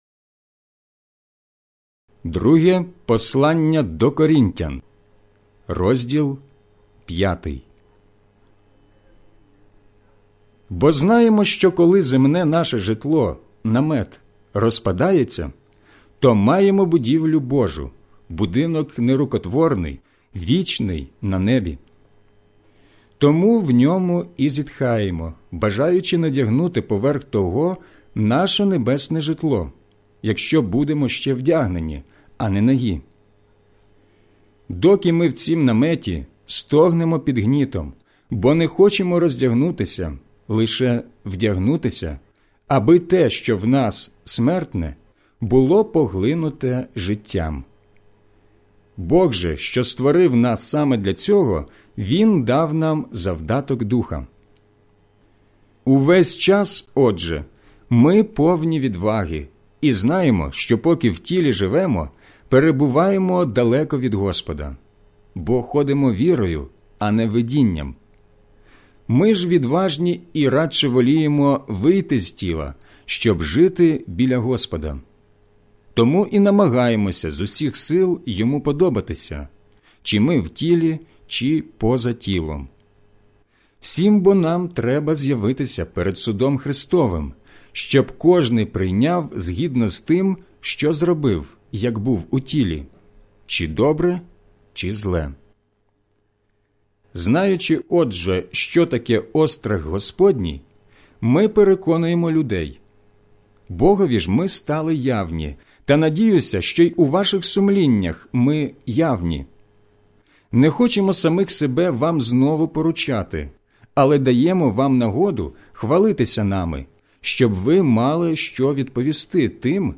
аудіобіблія